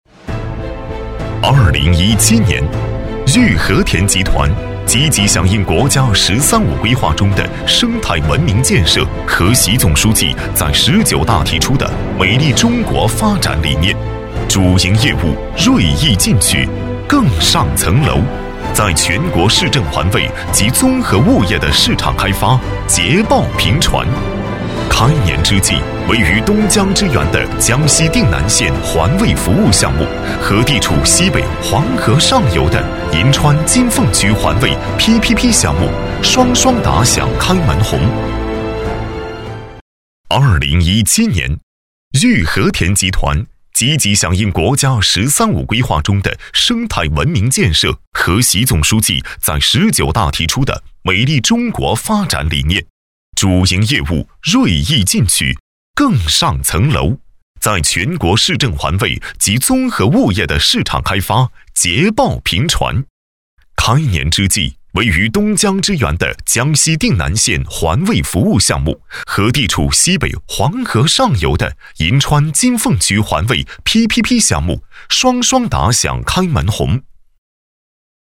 标签： 激情
配音风格： 温情 活力 稳重 讲述 浑厚 激情 时尚